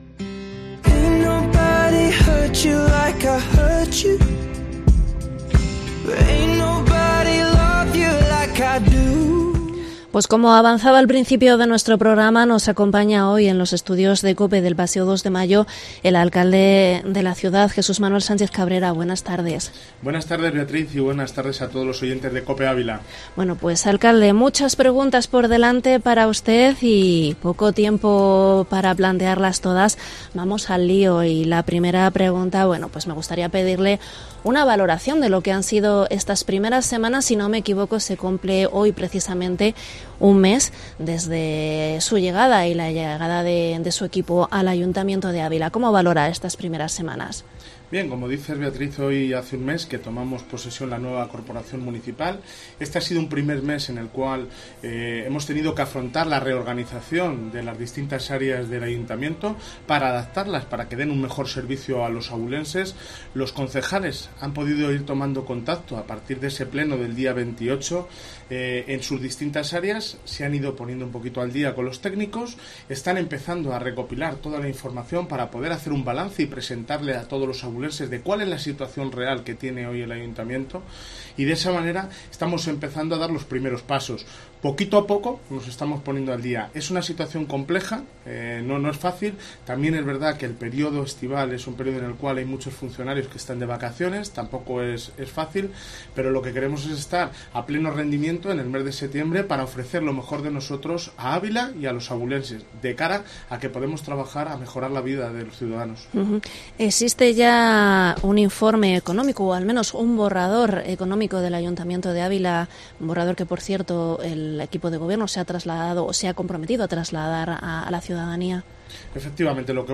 Entrevista Jesús Manuel Sánchez Cabrera